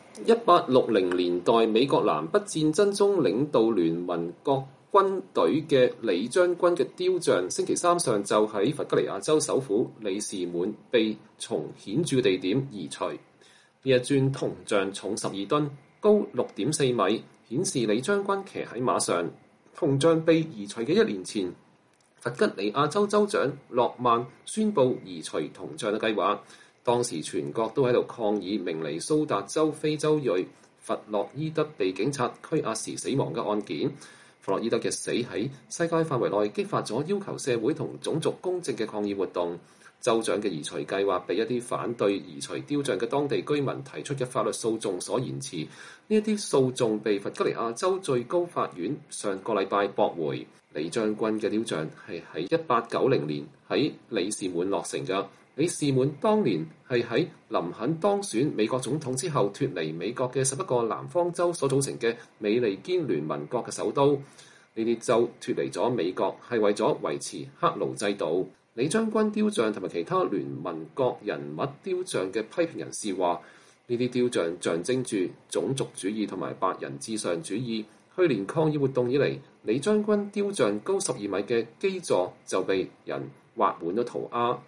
李將軍雕像在弗吉尼亞州首府里士滿被移除後當地民眾的反應（2021年9月8日）